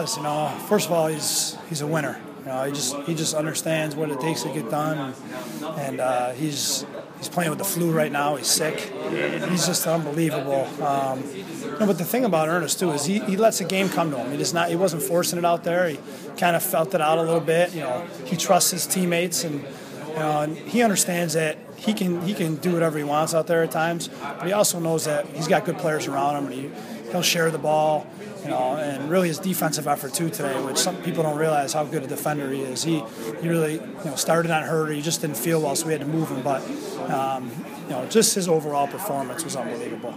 MP3 audio Postgame comments from the Aquinas locker room: